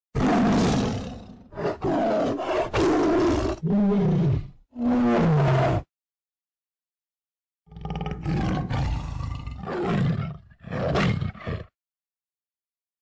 ▷ SONIDO de TIGRE rugiendo
El majestuoso sonido deun tigre rugiendo
Aquí encontrarás el sonido inconfundible del tigre, poderoso, imponente y salvaje.
Sonido-tigre-rugiendo.wav